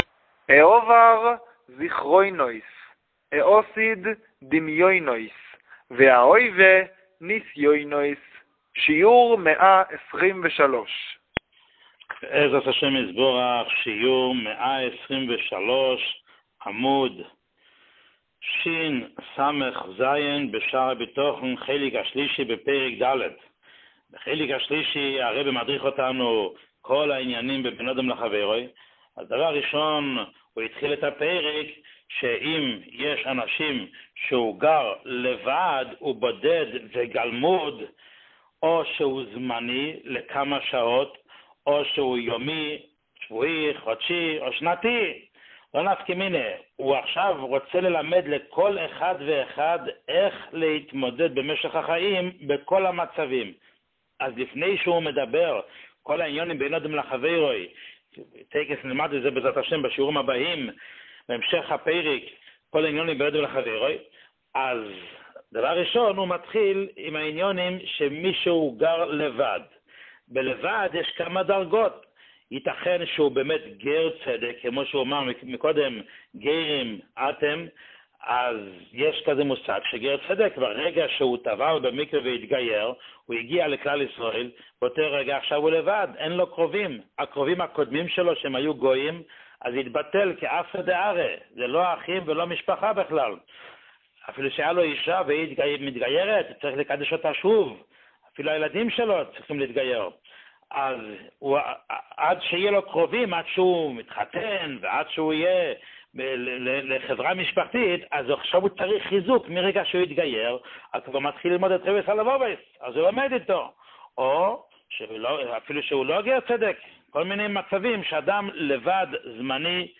שיעור 123